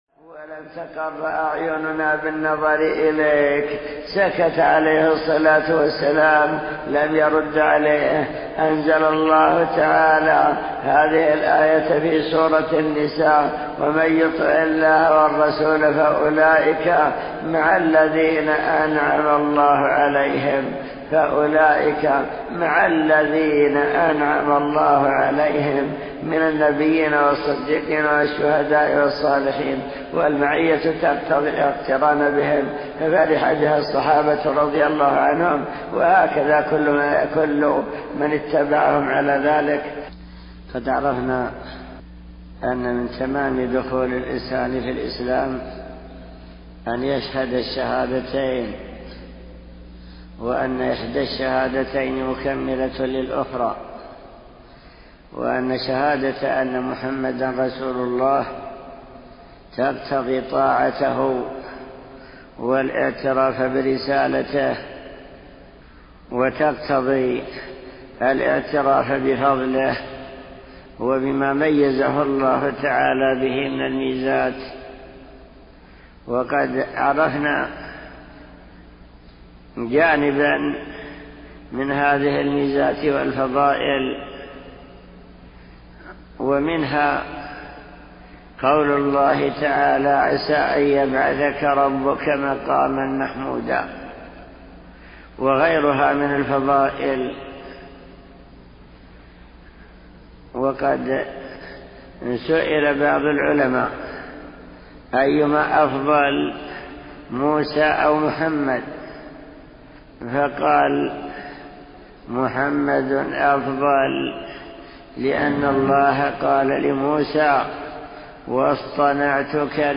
أرشيف الإسلام - ~ أرشيف صوتي لدروس وخطب ومحاضرات الشيخ عبد الله بن عبد الرحمن بن جبرين